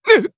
Siegfried-Vox_Damage2_jp.wav